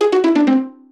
jingles-pizzicato_12.ogg